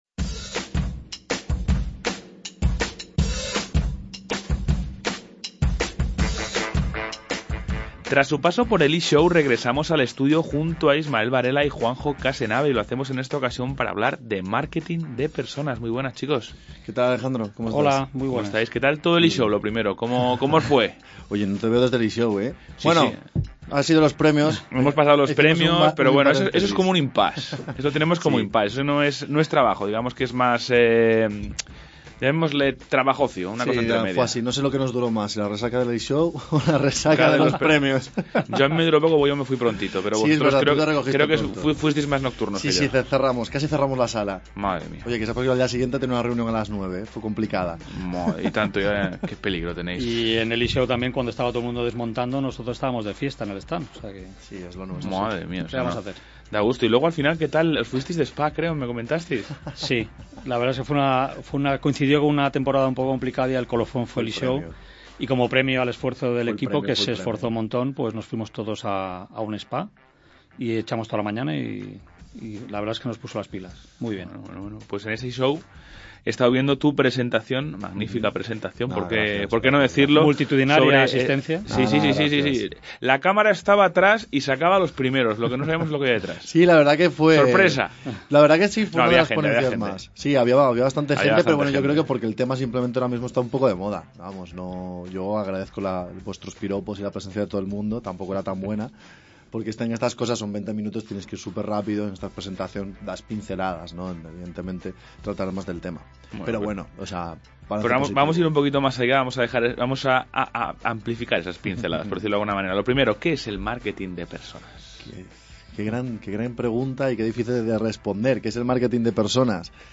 Y como nos daba algo de envidia, hemos querido contar con él en nuestros estudios, de cara a profundizar un poquito más en esta disciplina.